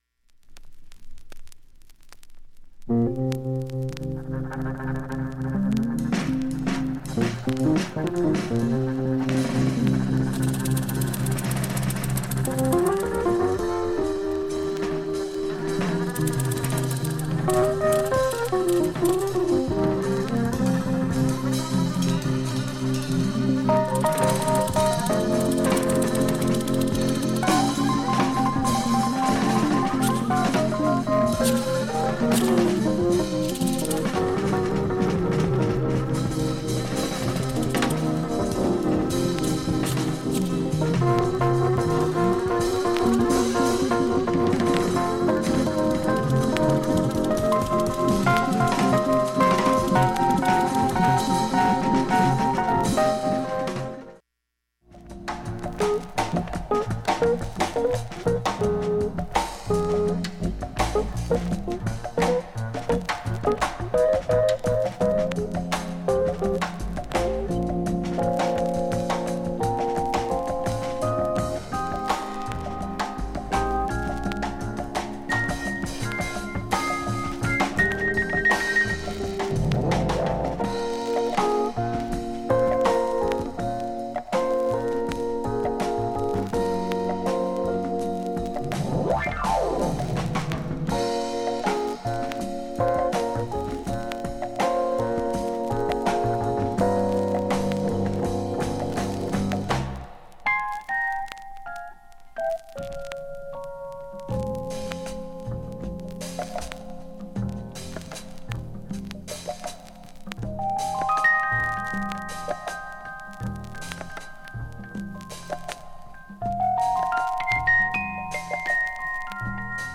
A-1,2,3B-1,2曲間無音部などにチリ出ますが
プツ音などもストレス無く
1,A-1始めかすかなチリ出ます。
110秒の間に周回プツ出ますがかすかです。
５０秒間かすかなプツが出ます
A-4後半までかすかで、ずっとなってます。
現物の試聴（上記録音時間7m06s）できます。音質目安にどうぞ
10回までのかすかなプツが２箇所
単発のかすかなプツが１０箇所